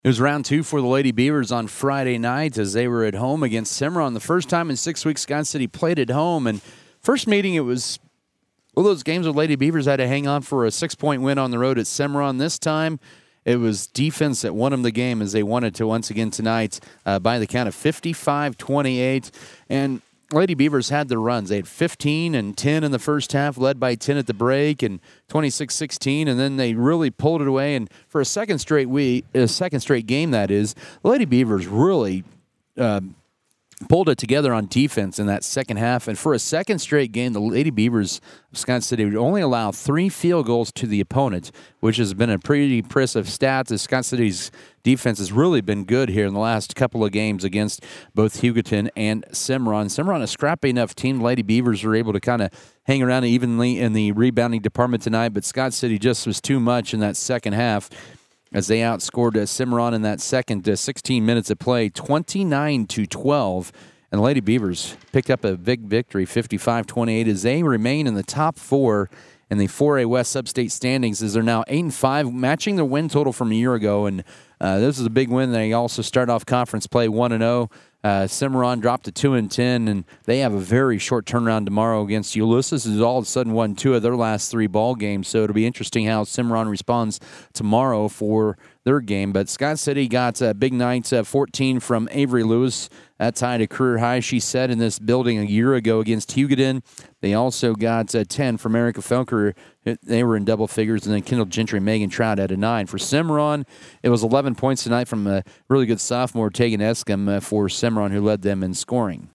Girls Audio Recap
GIRLS-AUDIO-RECAP-1-26-24.mp3